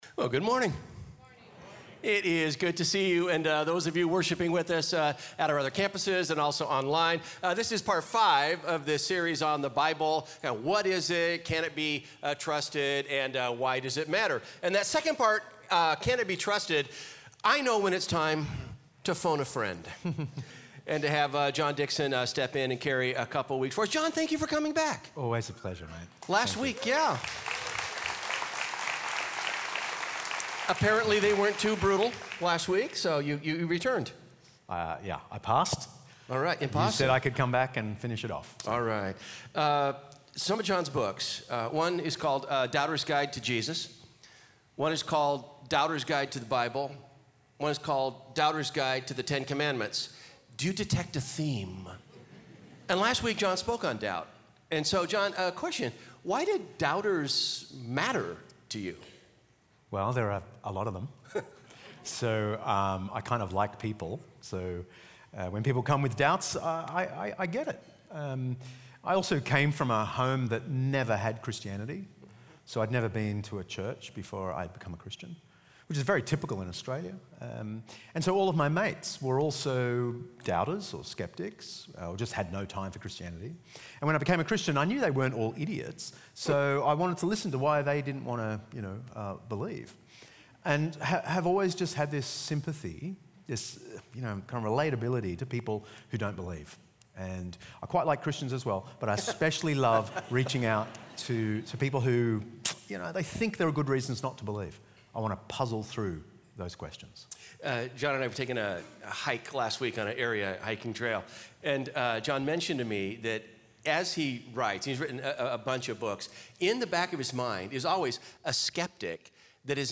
Guest Speaker, Pastor John Dickson, shares reasons we have to trust the Bible.